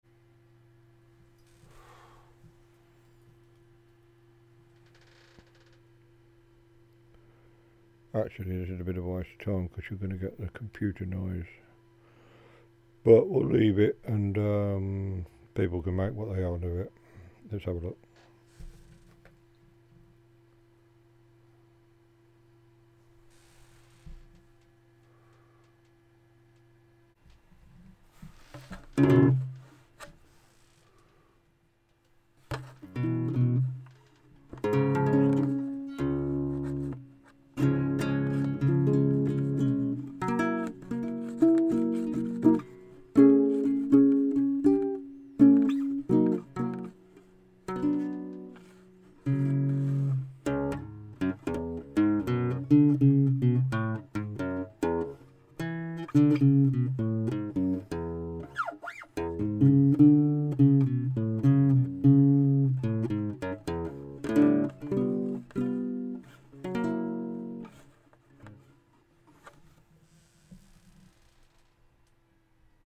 Mic is into the KA6 and recorded with Samplitude SE8. The guitar is a rubbish acoustic with nylon strings and the player is even rubbisher! The noise floor is spoiled a bit by the time of day (nowish) and the whine I get from the desktop PC. My Sontronics STC-2 LDC gives about the same noise level but the Mackie is a bit more sensitive.